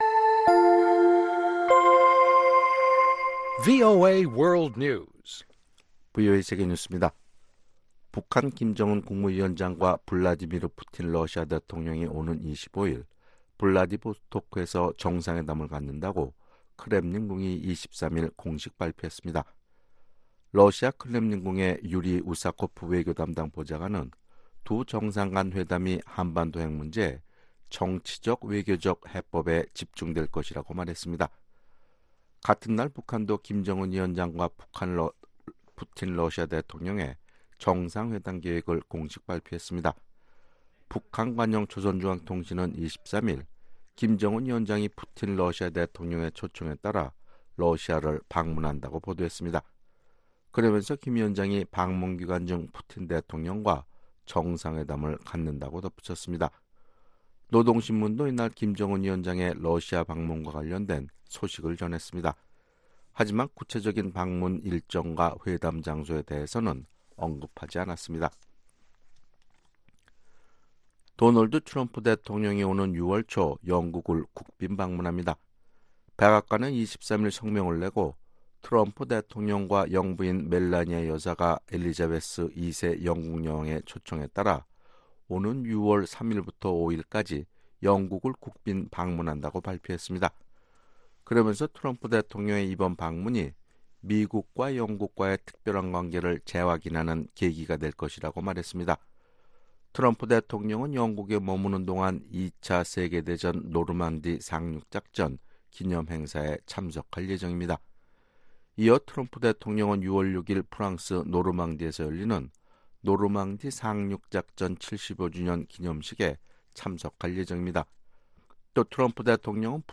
VOA 한국어 아침 뉴스 프로그램 '워싱턴 뉴스 광장' 2019년 4월 24일 방송입니다. 북한은 오늘 관영 ‘조선중앙통신’을 통해 김정은 위원장의 러시아 방문을 공식 발표했습니다. 미국 사법 당국이 지난 2월 발생한 스페인 주재 북한대사관 습격 사건에 관련된 한국계 미국인 한 명을 체포한 것으로 알려졌습니다.